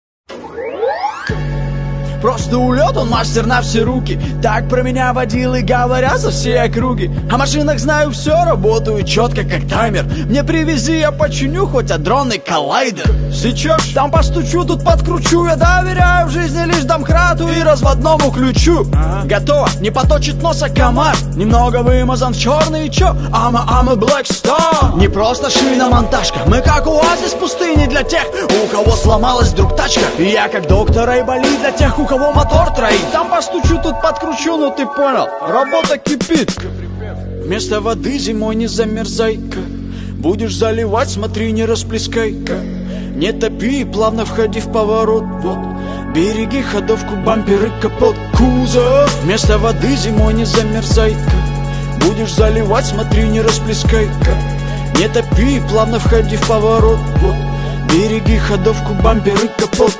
русский рэп
веселые
Trap
пародия
Гаражный рэп